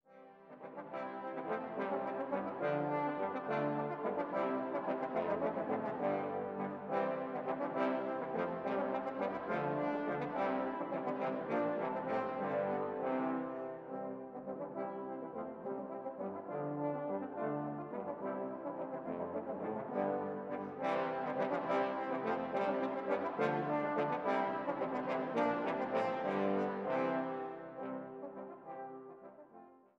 Posaunenquartett